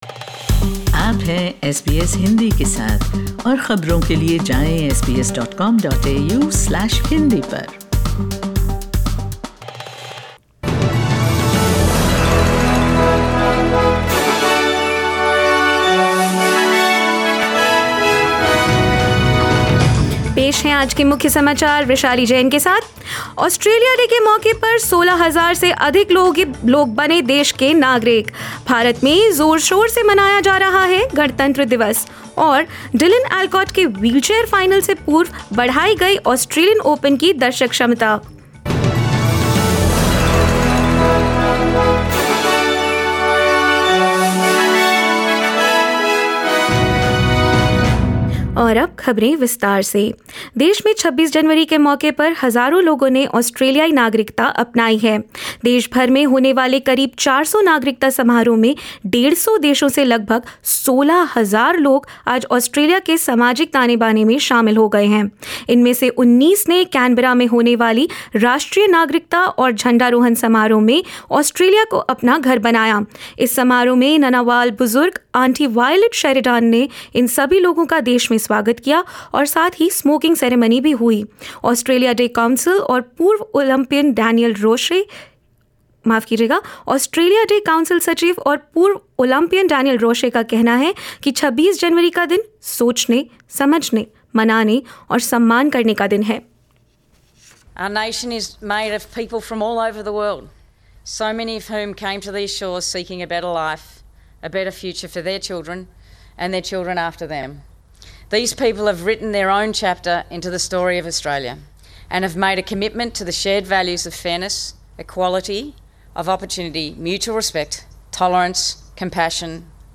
In this latest SBS Hindi bulletin: Australia welcomes over 16,000 new citizens from 150 countries; Australia Day protests carried out throughout the nation; India celebrates its 73rd Republic Day; Australian Open increases crowd capacity as Australian of the Year Dylan Alcott plays his wheelchair final tomorrow and more.